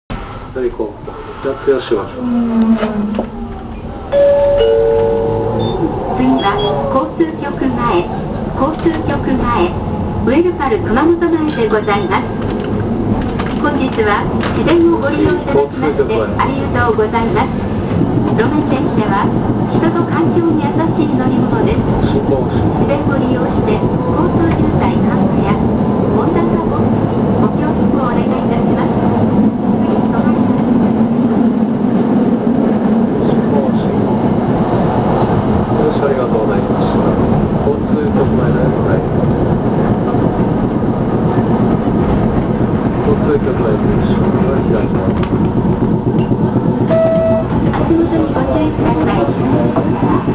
・1350形走行音
【Ａ系統】九品寺交差点〜交通局前（50秒：288KB）…1355にて
勿論吊り掛け式です。路面電車の割に少々重い音がする印象。